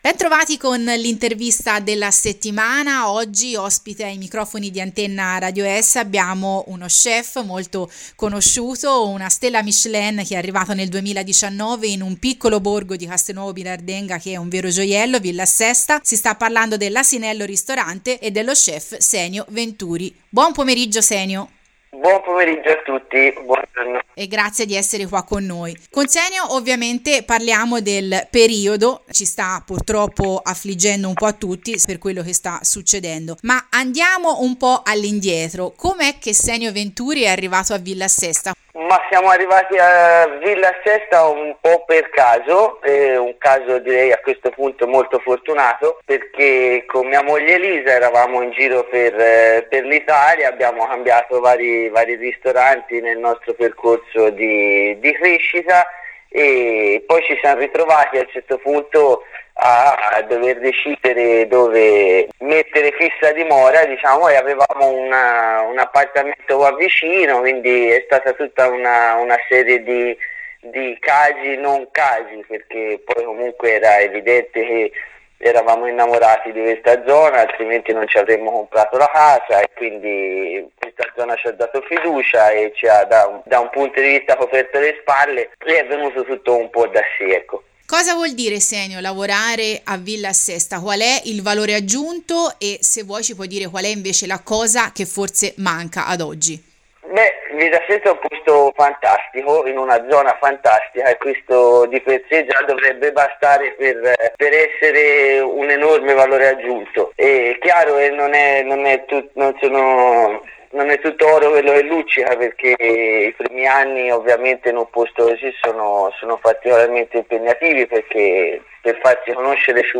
Facebook Posts Interviste
Sotto l’intervista completa.